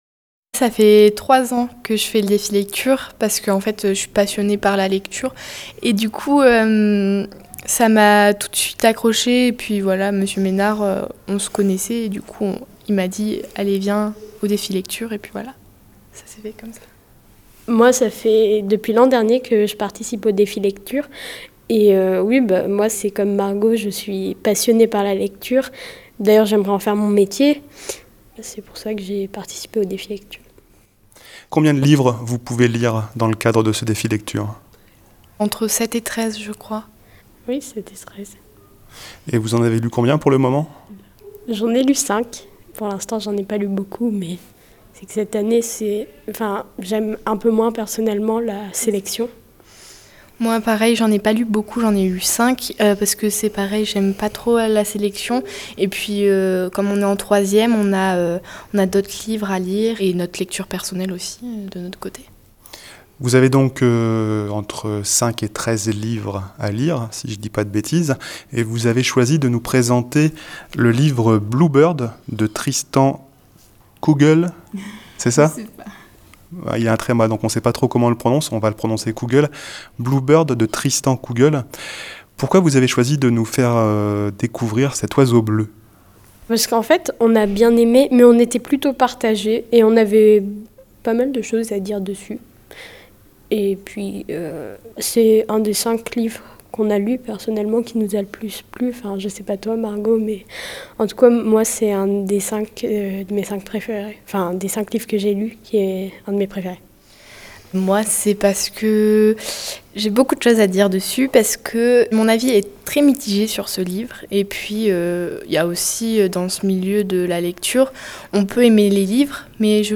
L’émission spéciale